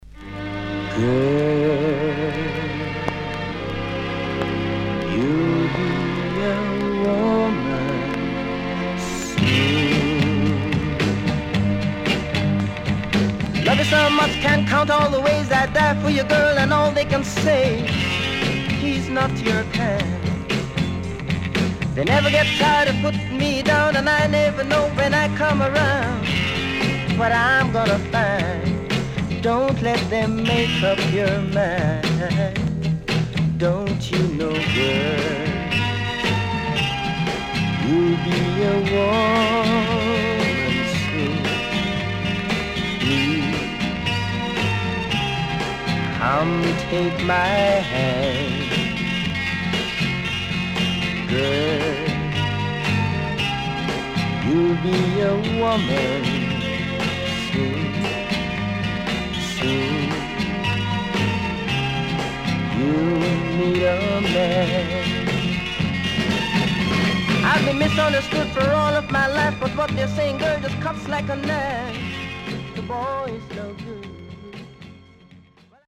HOME > Back Order [VINTAGE 7inch]  >  KILLER & DEEP
CONDITION SIDE A:VG(OK)
Killer Inst & Soulful Vocal
SIDE A:曇りによるヒスノイズあります。少しプチノイズ入ります。